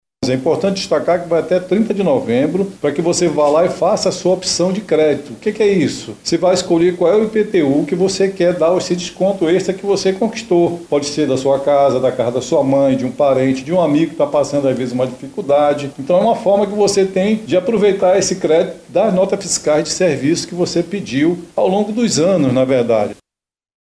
O abatimento total pode somar até 50% do IPTU do exercício. Como explica o subsecretário da Secretaria Municipal de Finanças e Tecnologia da Informação (SEMEF), Armínio Pontes.
Sonora-Arminio-Pontes-subsecretario-da-Semef.mp3